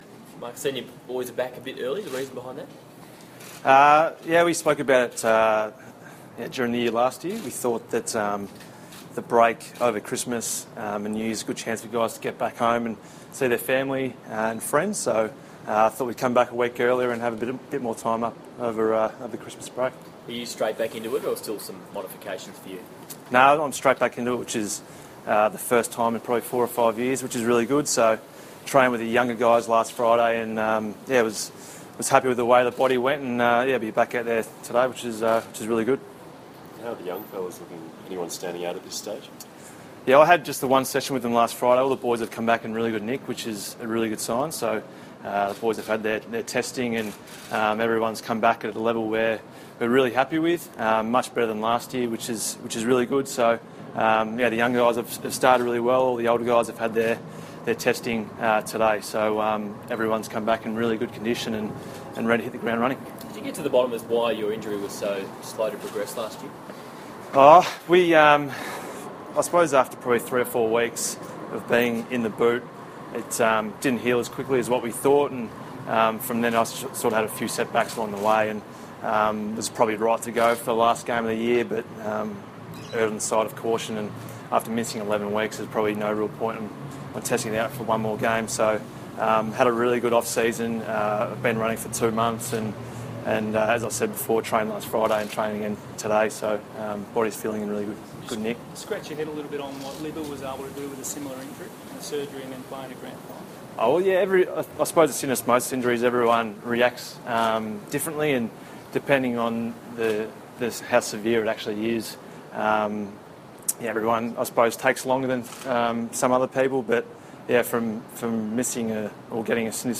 Carlton captain Marc Murphy fronts the media on day one of 2017 pre-season.